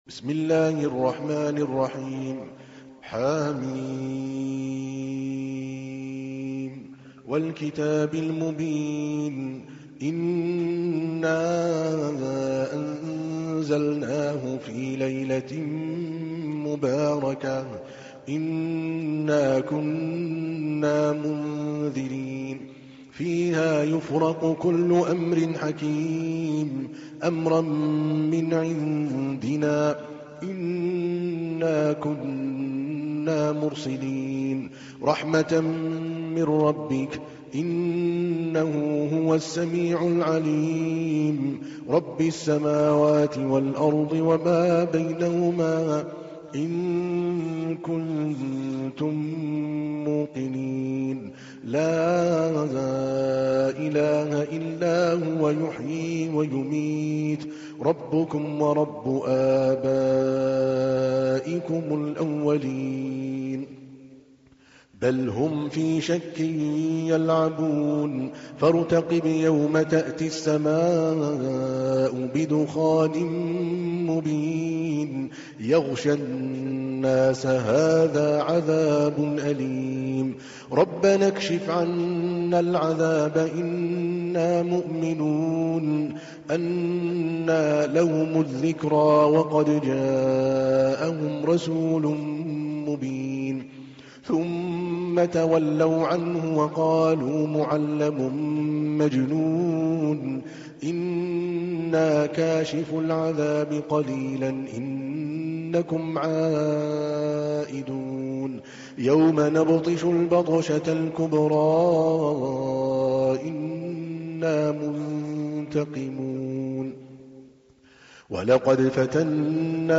تحميل : 44. سورة الدخان / القارئ عادل الكلباني / القرآن الكريم / موقع يا حسين